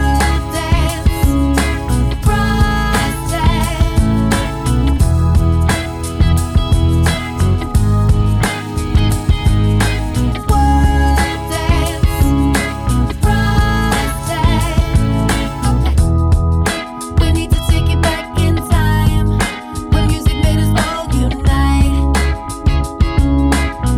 No Rap One Semitone Down Pop (2010s) 3:42 Buy £1.50